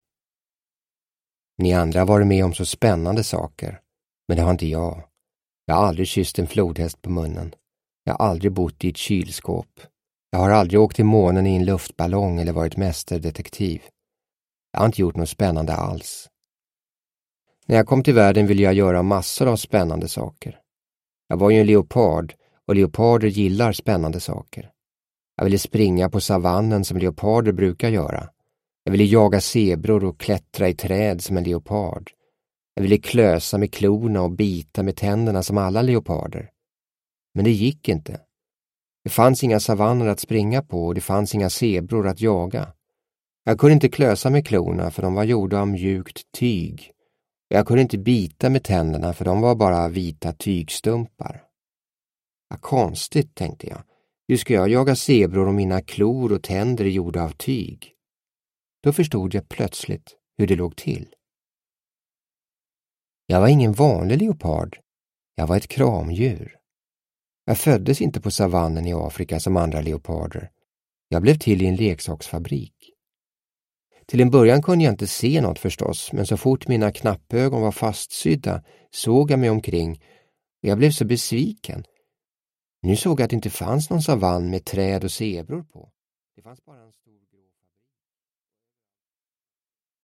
Rea Leopard – Ljudbok – Laddas ner